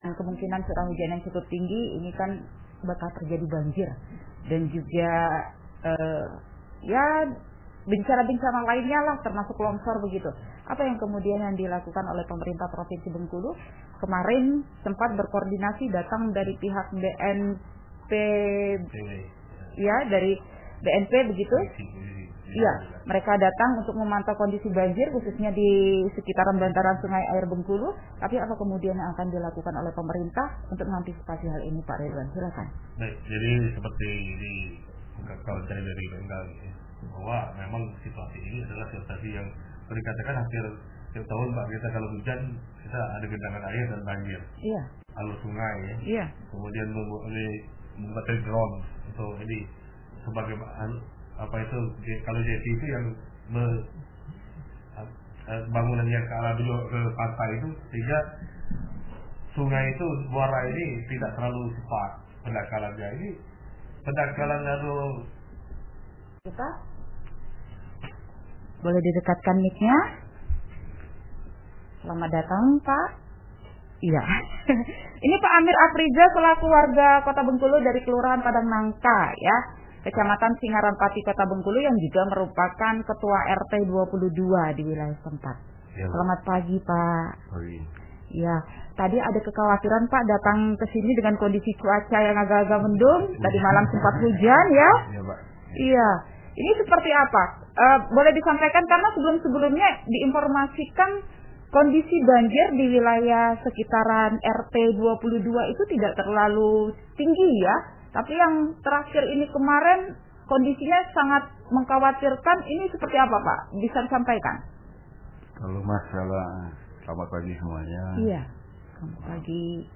Programa : Pro 1 / 92.5 Fm